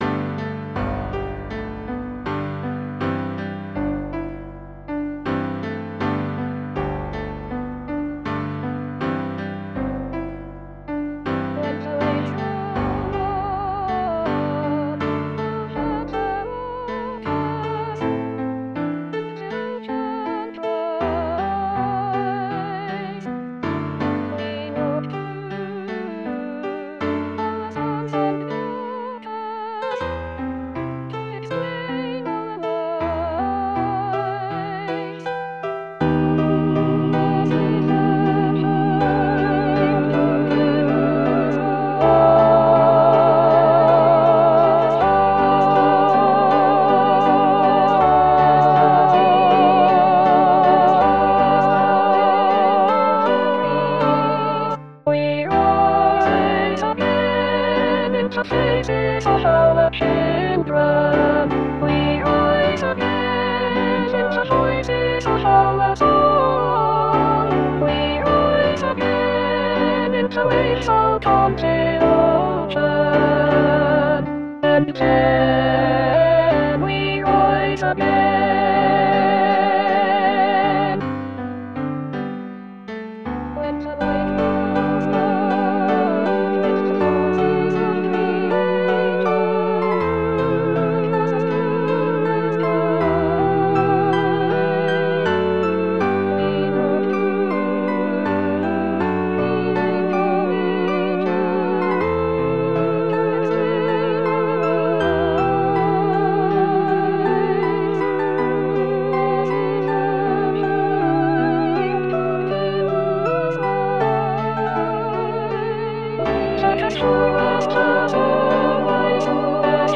The Music of Stillness (Fast – 1/4 note = 100)